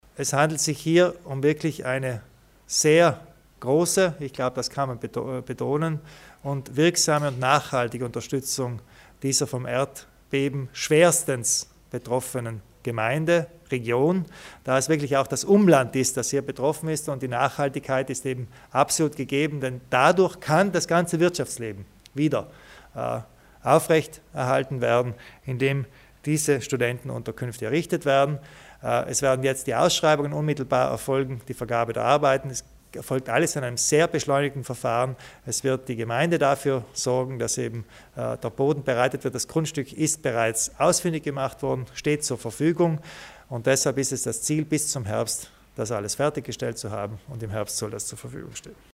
Landeshauptmann Kompatscher zu den Hilfeleistungen im Erdbebengebiet